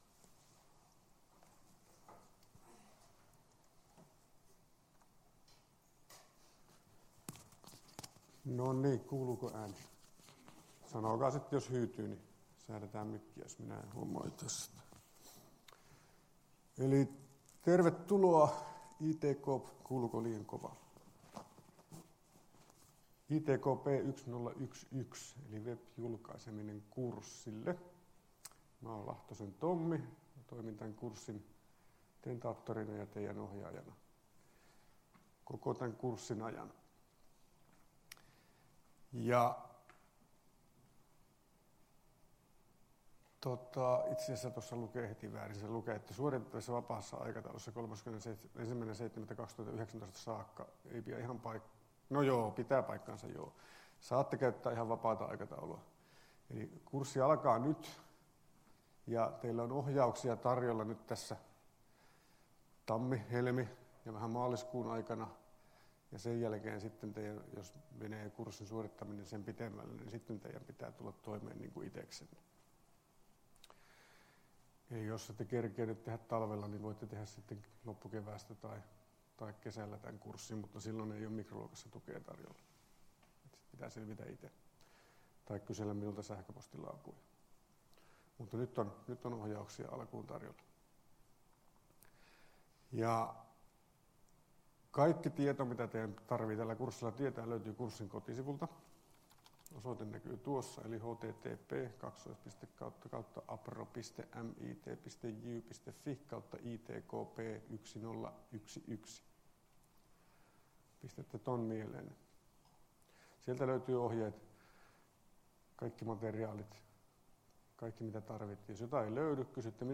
Luento 16.01.2019 — Moniviestin